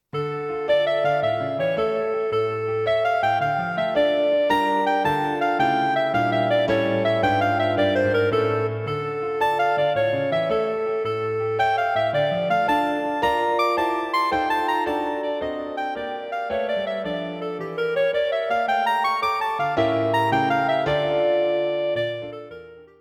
The audio clip is the third movement - Allegretto.